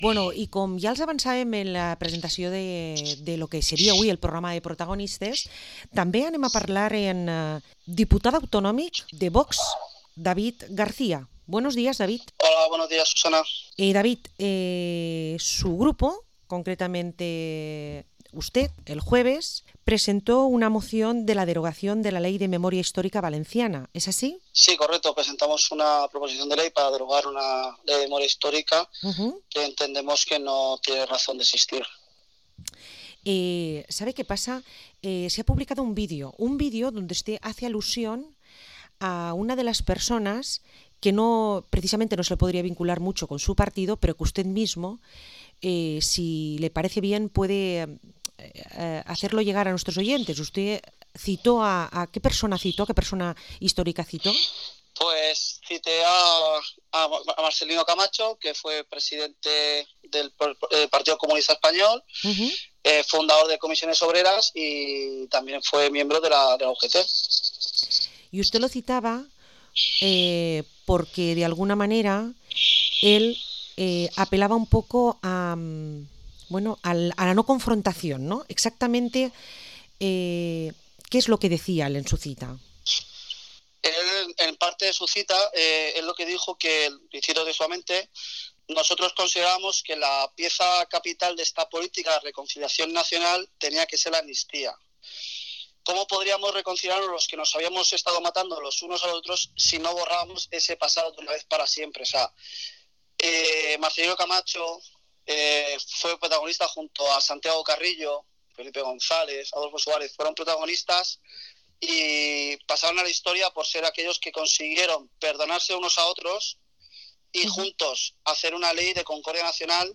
Entrevista al diputado autonómico por Vox, David García